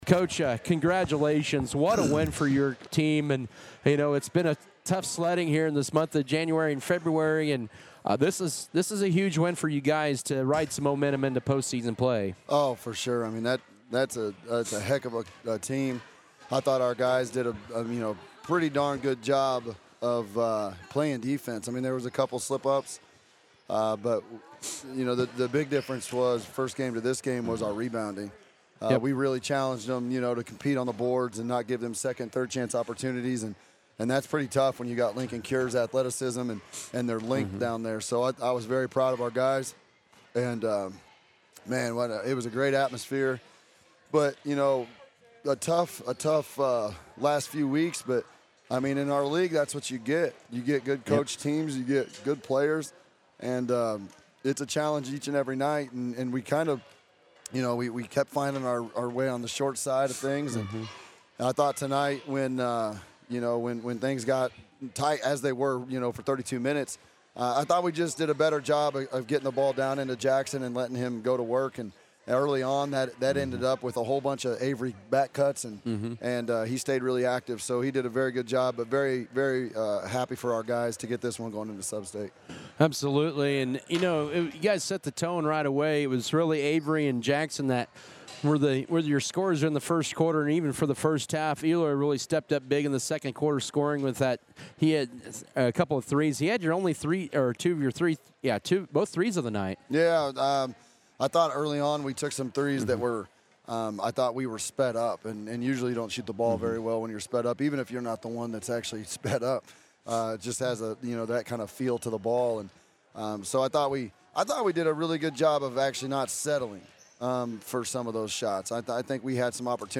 Postgame